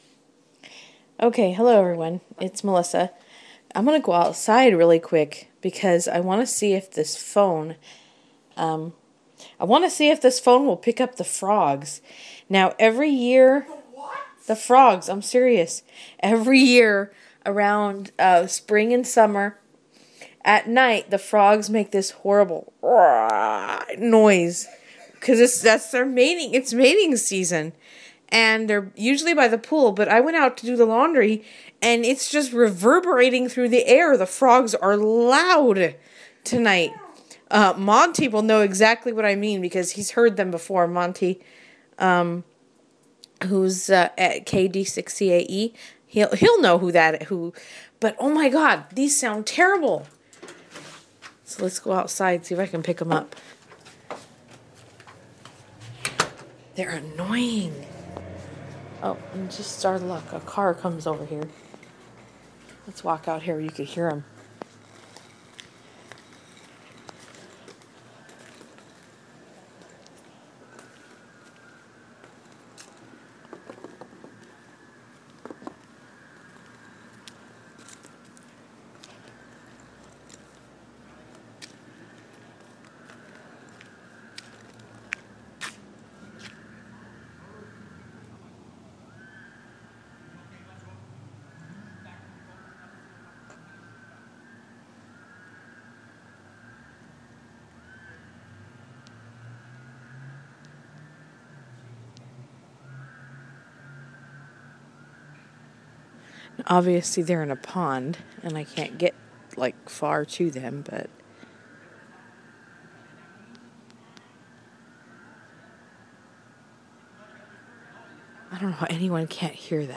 Loud, noisy frogs in the distance. It's mating season and they're active tonight!